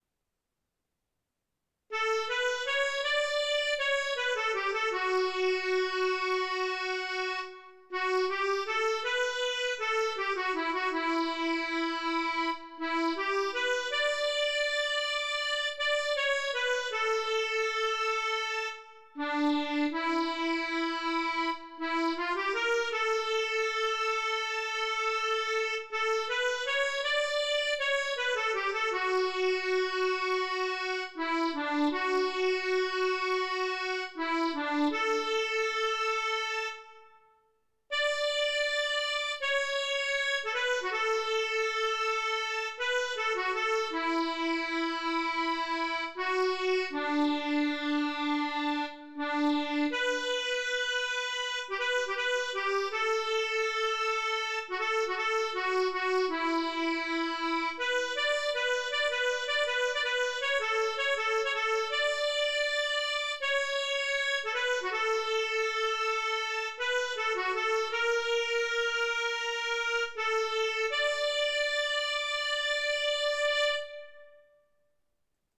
Blida-ville-d_amour-Accordéon.mp3